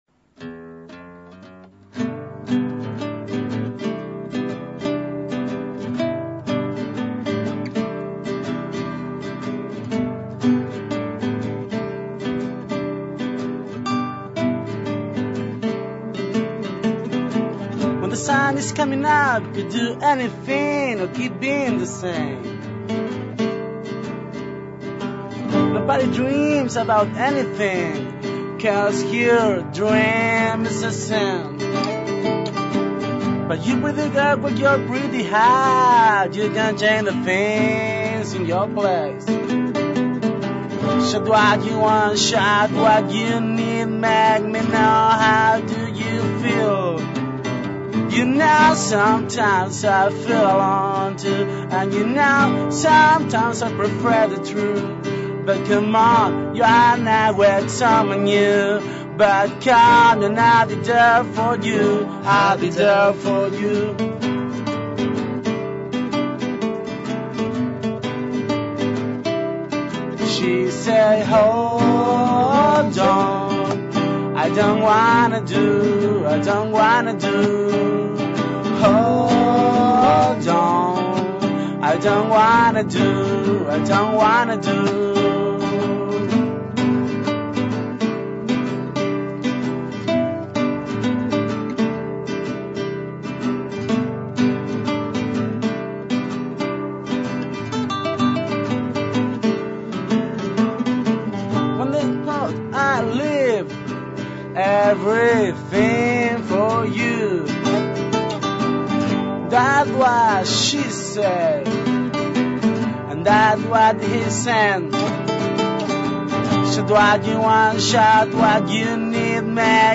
Para conocer su propuesta la banda visitó Suena Tremendo.